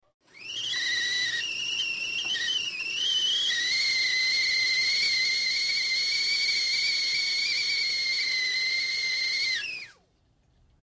Boiling Liquid With Kettle Whistle Gradually Becoming Louder And Higher In Pitch, X2